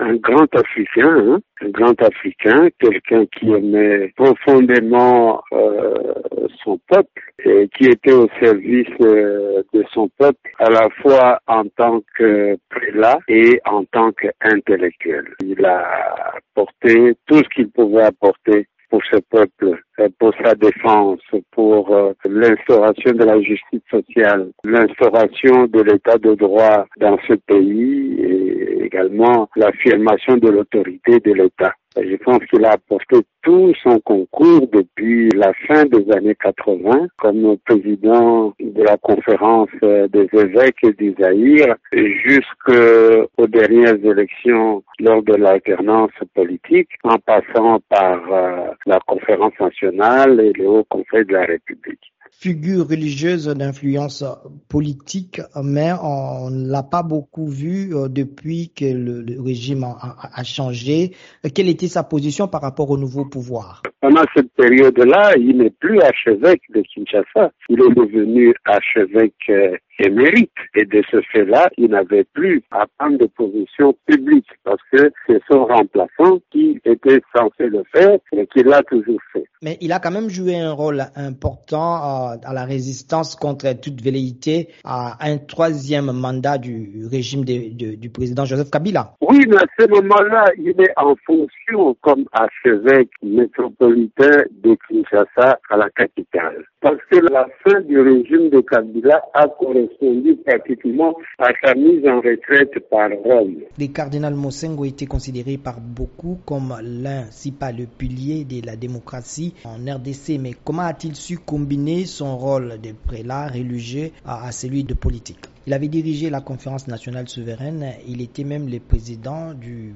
l’historien et professeur d’université